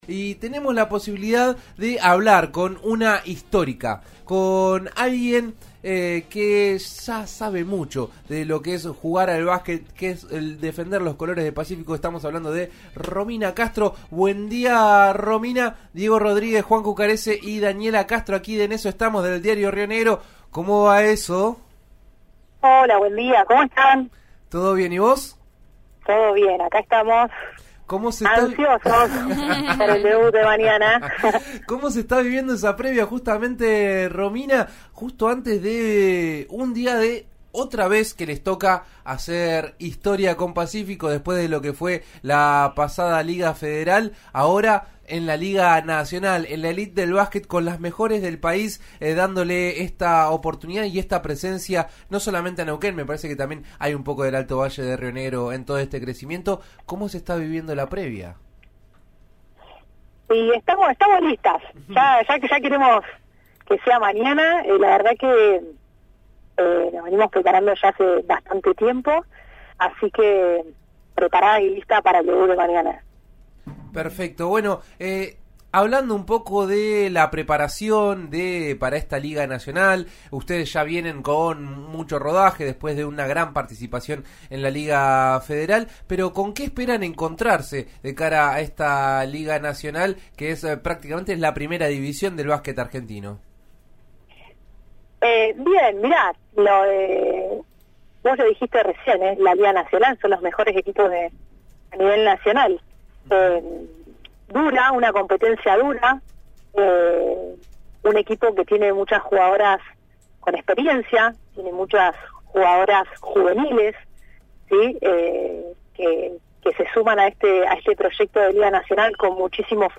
La jugadora de Pacífico, equipo que hizo historia en el básquet femenino de la región, habló con En Eso Estamos por RN Radio.